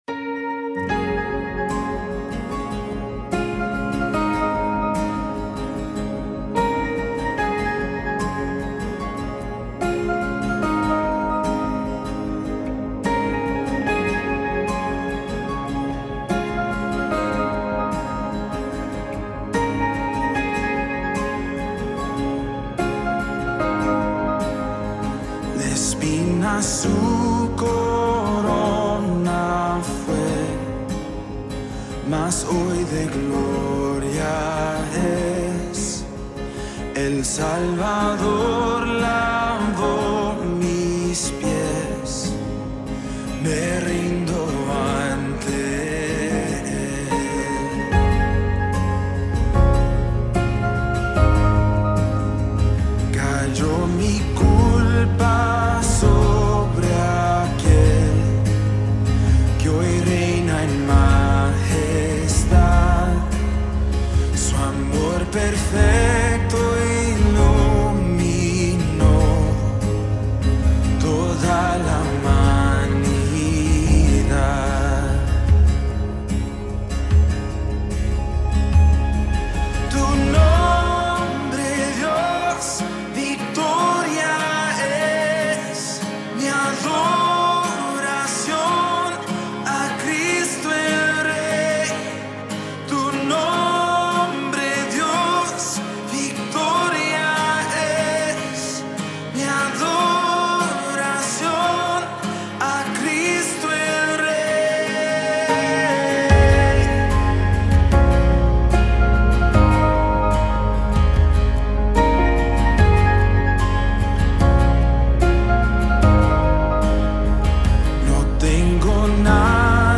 90 просмотров 104 прослушивания 5 скачиваний BPM: 74